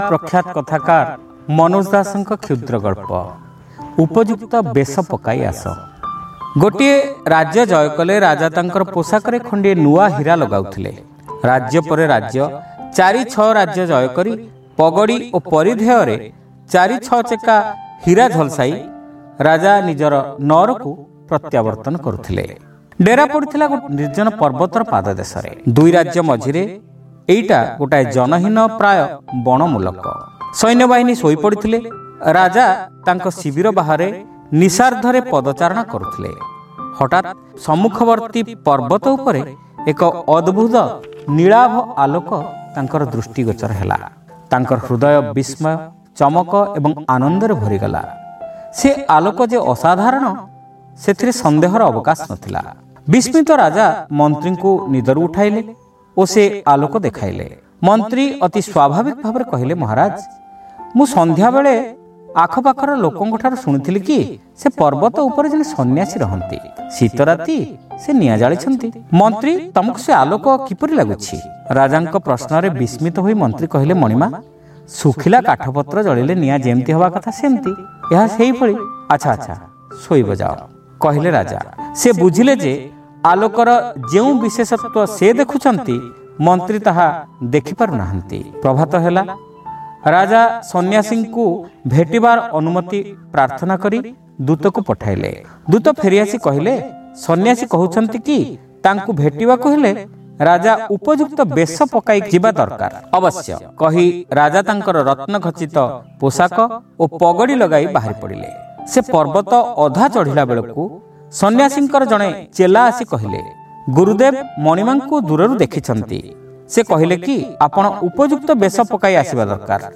ଶ୍ରାବ୍ୟ ଗଳ୍ପ : ଉପଯୁକ୍ତ ବେଶ ପକାଇ ଆଶ